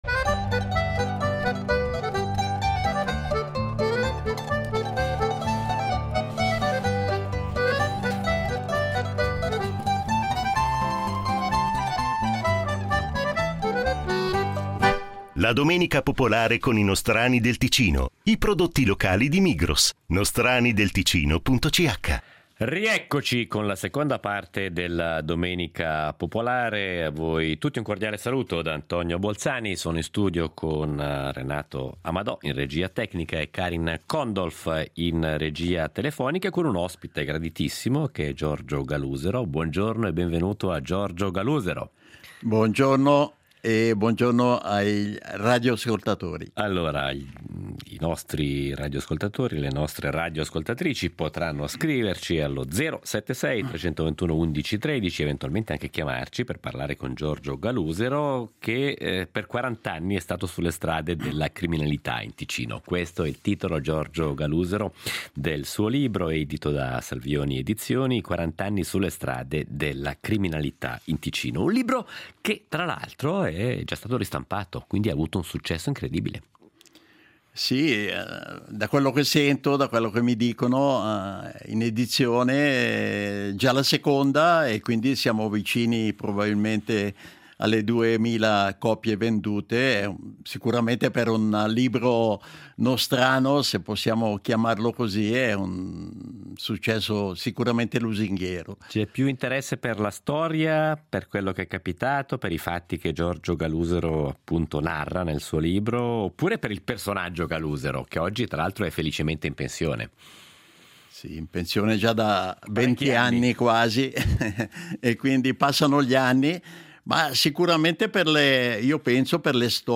La domenica popolare Intervista